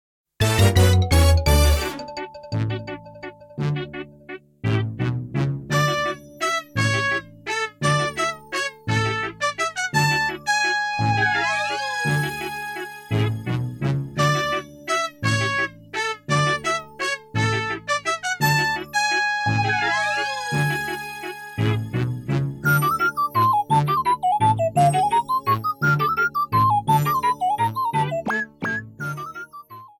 Applied fade-out